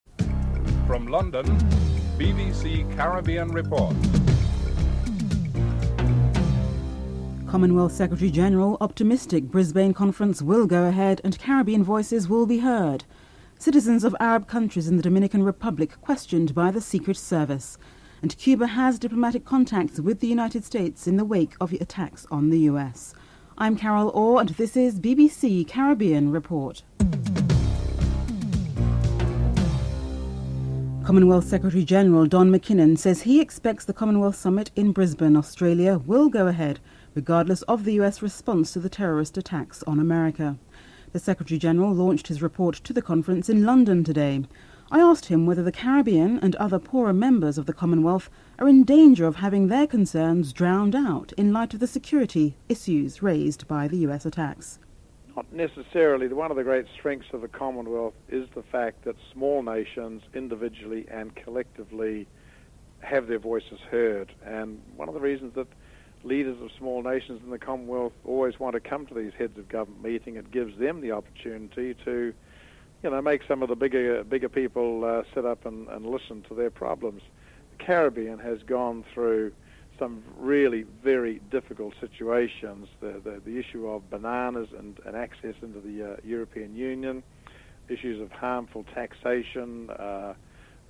1. Headlines (00:00-00:30)
2. Commonwealth Secretary General Don McKinnon is optimistic that the Brisbane Conference will go ahead and Caribbean voices will be heard. Commonwealth Secretary General Don McKinnon is interviewed (00:31-03:37)